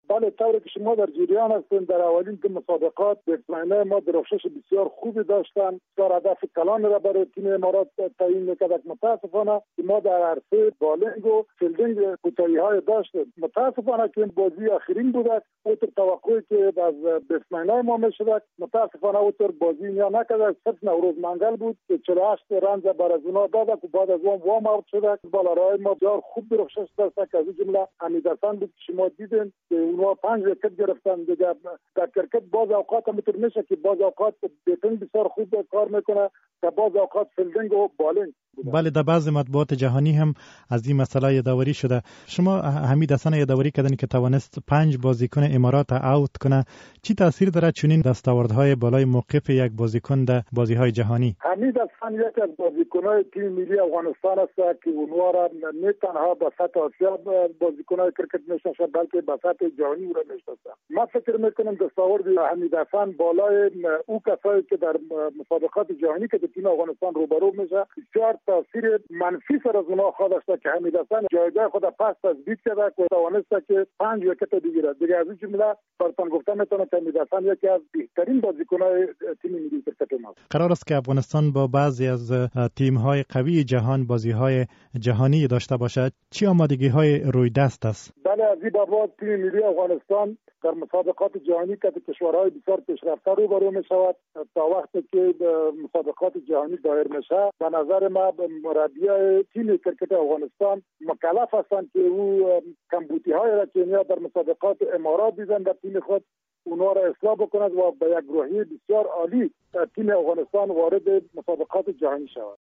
مصاحبه در مورد آمادگی های تیم ملی کرکت افغانستان برای مسابقات جهانی!